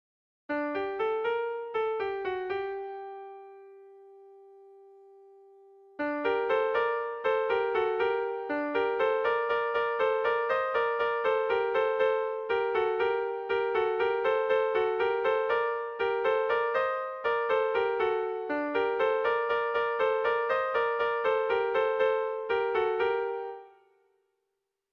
Sentimenduzkoa
Arantzazuko doinu popularra, Salbatore Mitxelenaren bertsoz hornitua.
Zortzikoa, berdinaren moldekoa, 6 puntuz (hg) / Sei puntukoa, berdinaren moldekoa (ip)
ABDEFD